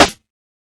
Tuned drums (C key) Free sound effects and audio clips
• 2000s Subtle Reverb Acoustic Snare Sound C Key 22.wav
Royality free snare drum sample tuned to the C note. Loudest frequency: 2090Hz